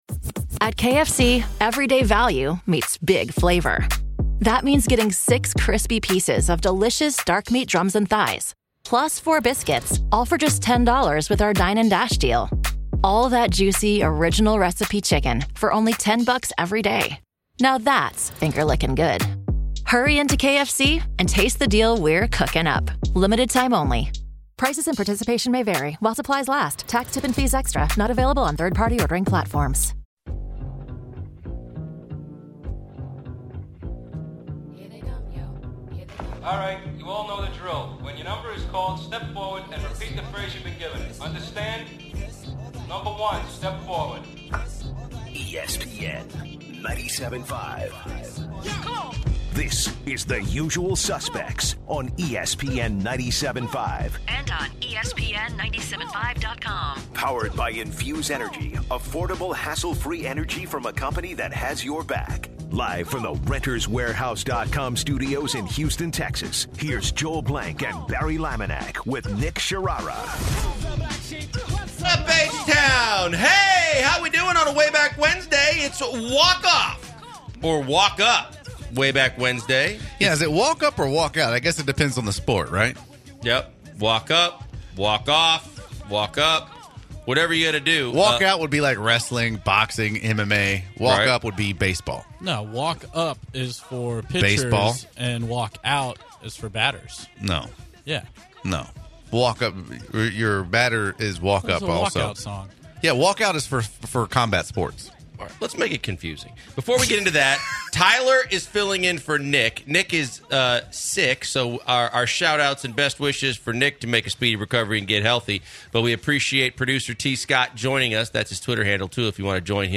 The guys take some calls on others’ thoughts about the Astrodome.